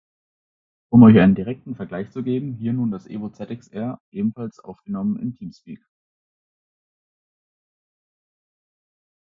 Das Mikrofon des Creative-Headsets nimmt durch die Lage verursacht sehr viele Außengeräusche neben der Stimme und auch Geräusche von Quellen hinter dem Benutzer auf. Der Klang beim Gesprächspartner wirkt sehr schal und hat oftmals deutlich vernehmbar ein Rauschen, welches zeitweise stark störend ist.
Um euch einen Eindruck von dem „glasklaren“ Sound zu verschaffen, wie er von Creative beworben wird, haben wir hier zwei Aufnahmen für euch vorbereitet, von denen jeweils eine mit dem EVO ZxR und mit dem Roccat Kave XTD aufgenommen sind.
EVO-ZXR-Teamspeak.wav